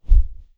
Close Combat Swing Sound 29.wav